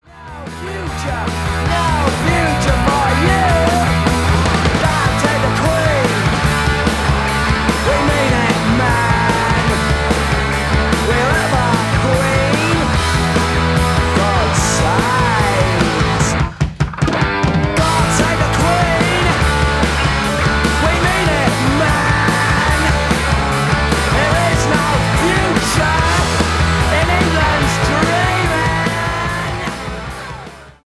рок , панк-рок , 70-е